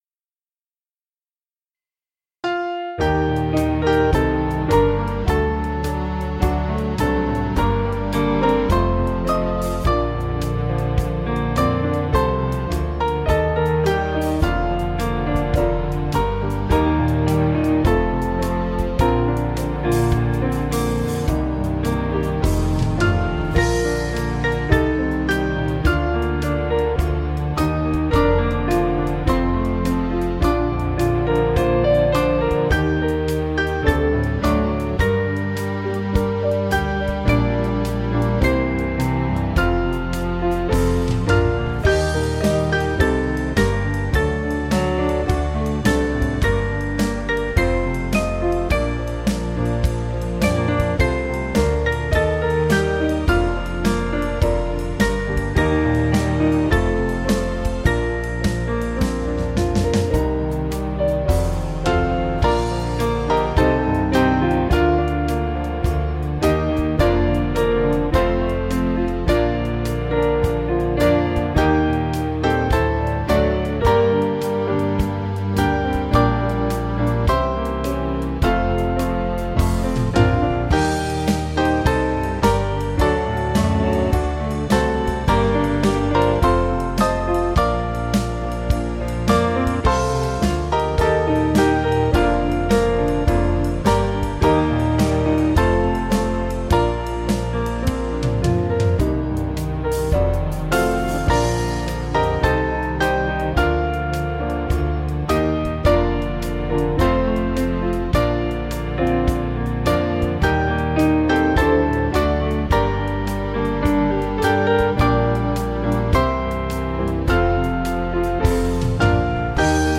Piano & Instrumental
(CM)   3/Bb